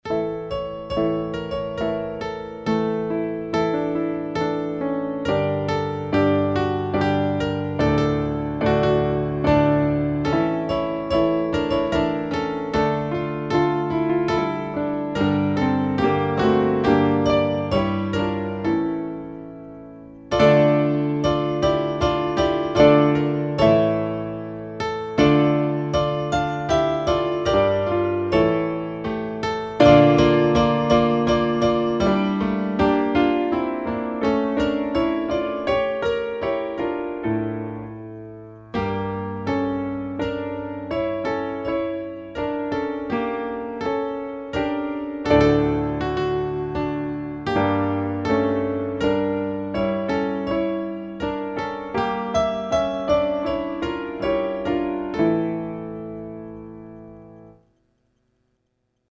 ביקשנו מהגולשים לזהות את הניגון, ואכן הגולשים פיענחו אותו כ"ניגון אבינו מלכנו". המוזיקאי החב"די